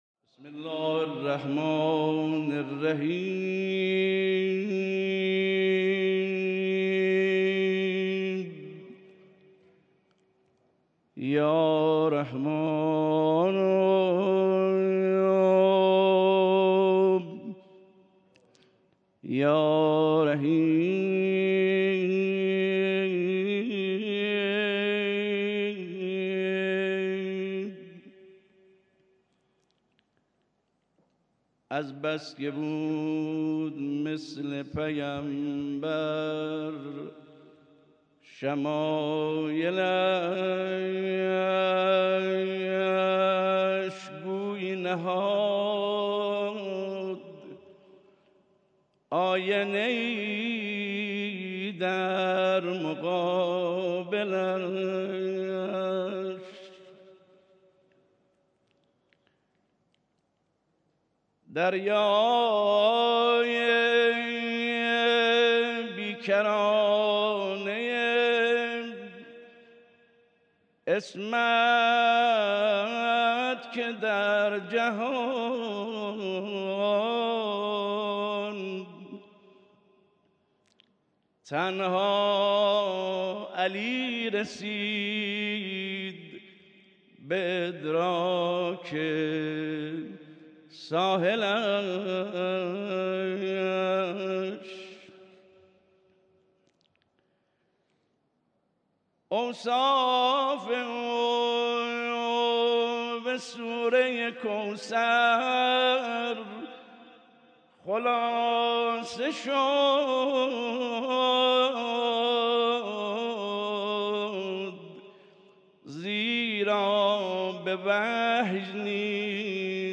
دیدار جمعی از مداحان و ذاکران اهل بیت علیهم‌السلام با رهبر انقلاب اسلامی